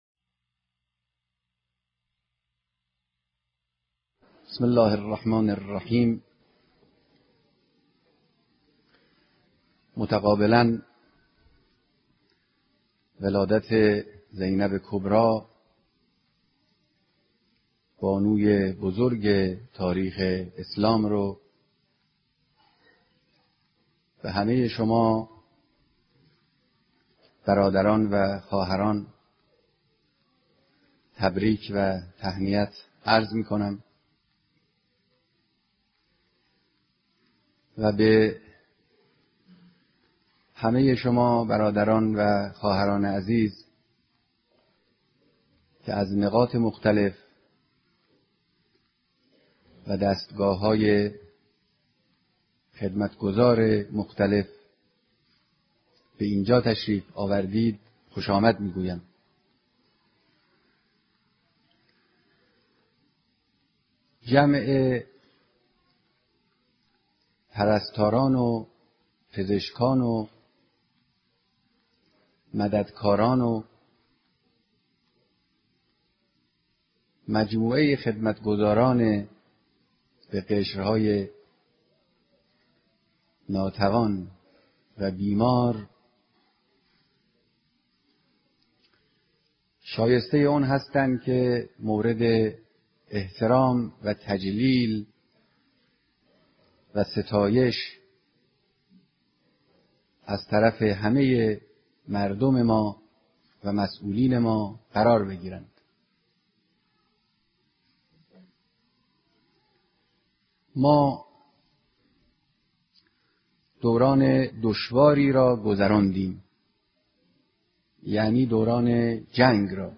صوت کامل بیانات
سخنرانى در ديدار با اقشار مختلف مردم و جمعى از پزشكان، پرستاران و كاركنان مراكز آموزشعالى و درمانى كشور